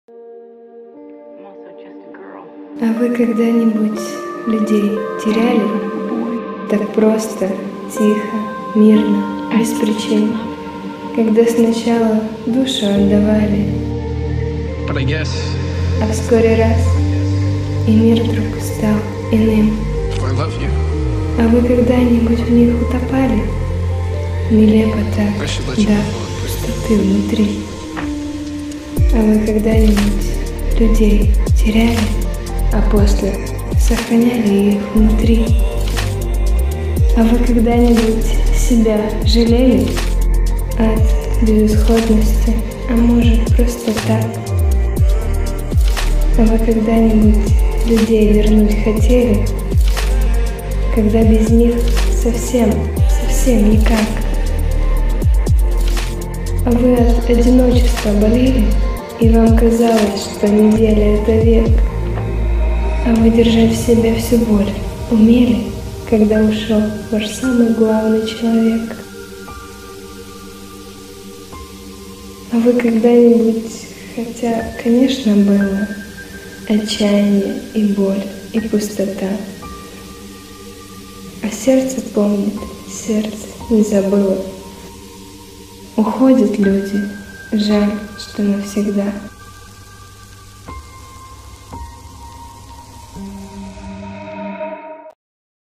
Песня создана с помощью нейросети